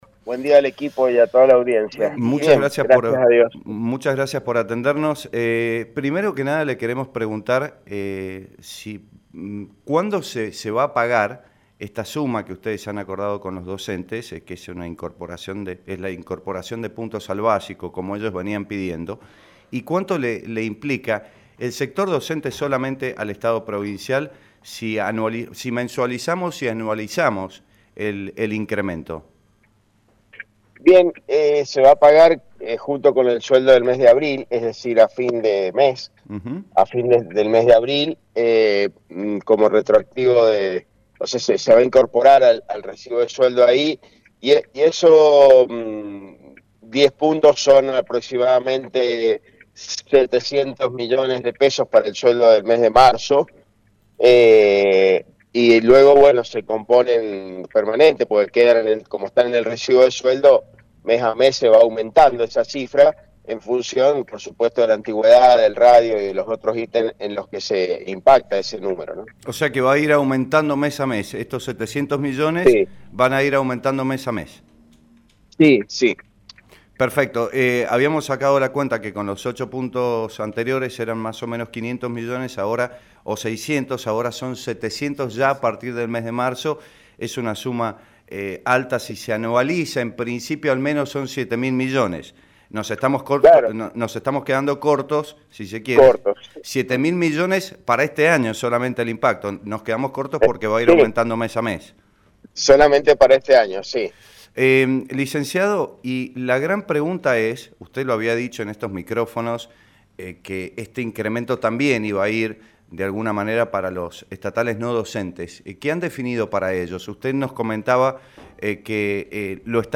En este contexto, el Secretario General de la Gobernación, Emilio Achem, dio detalles sobre el acuerdo con docentes y reapertura de paritarias. Además, adelantó que seguramente hoy habrá novedades con respecto a los empleados estatales.